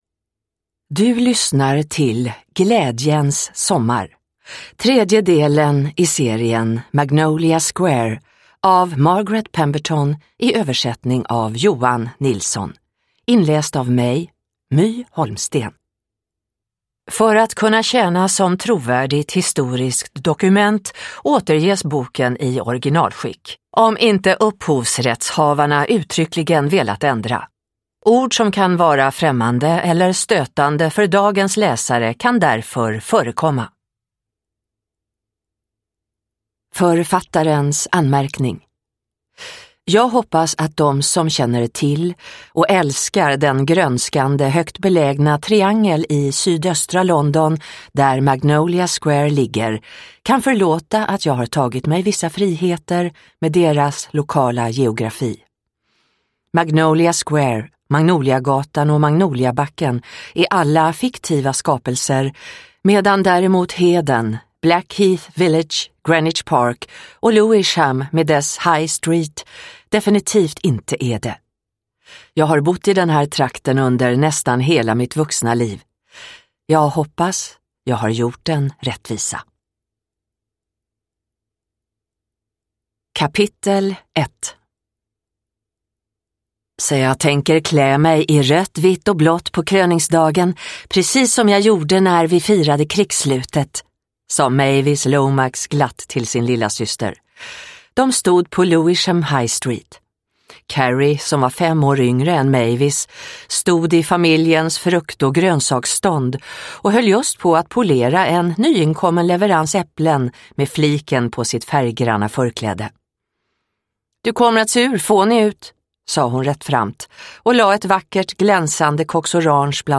Glädjens sommar – Ljudbok – Laddas ner